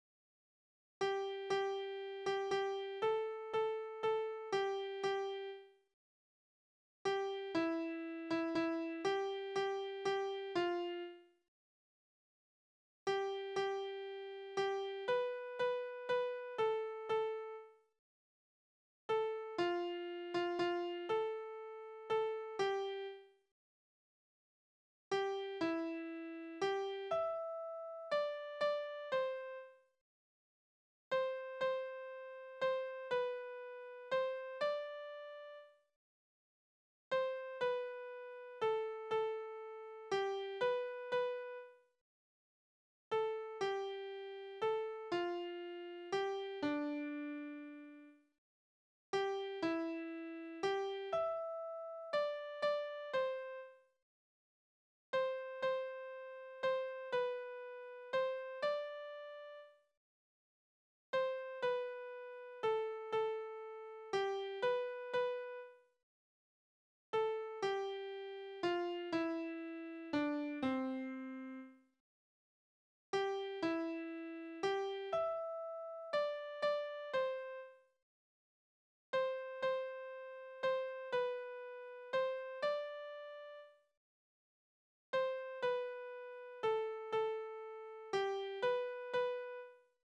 Balladen: Das Kind macht dem verlassenen Mädchen neuen Lebensmut
Tonart: C-Dur
Taktart: 3/4
Tonumfang: Oktave, große Sekunde